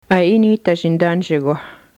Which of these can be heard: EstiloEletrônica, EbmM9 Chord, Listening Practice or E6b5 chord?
Listening Practice